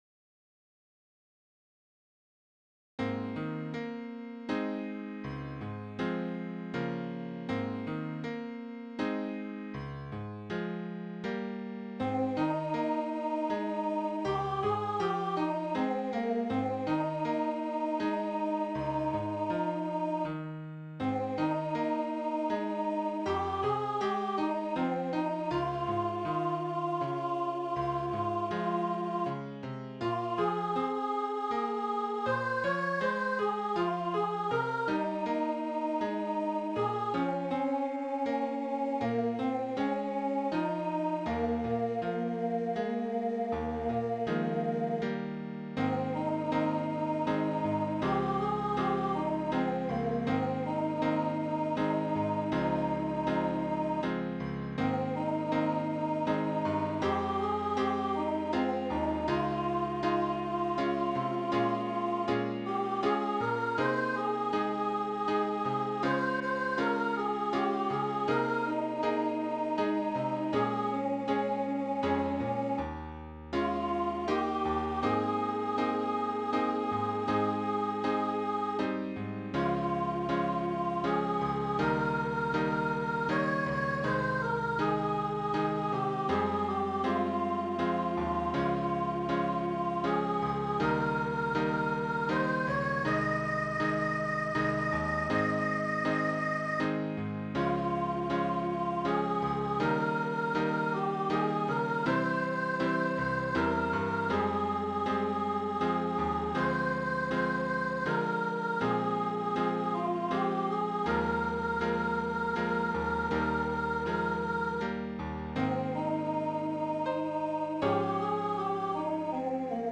Voicing/Instrumentation: Vocal Solo